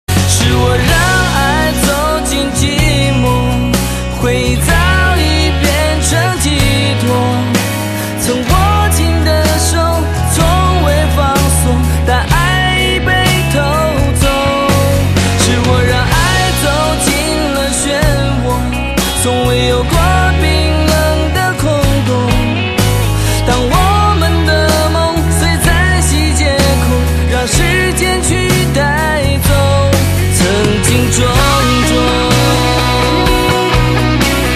M4R铃声, MP3铃声, 华语歌曲 53 首发日期：2018-05-15 11:05 星期二